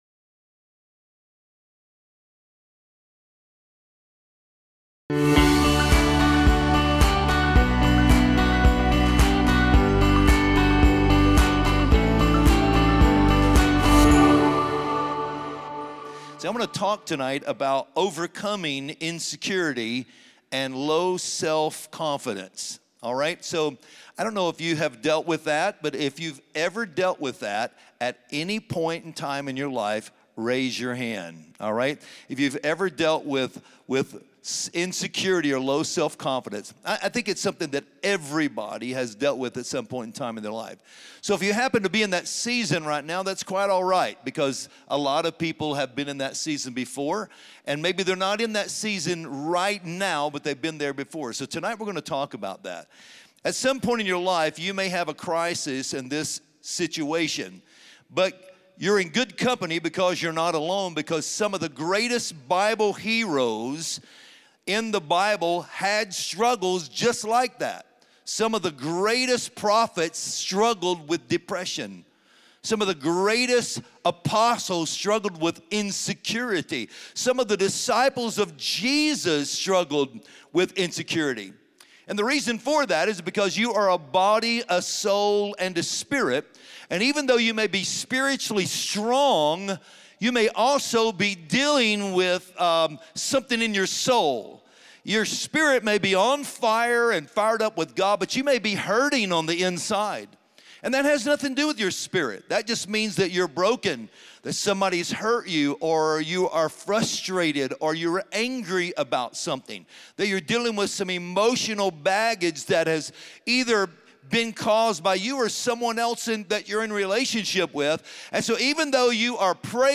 Join us this week for the sermon “You Are Not Alone .”